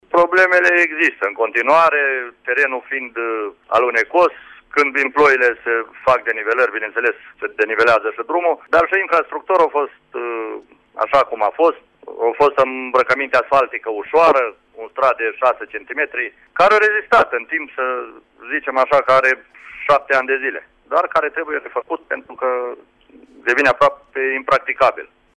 Primarul comunei Luncaviţa, Ion Velescu: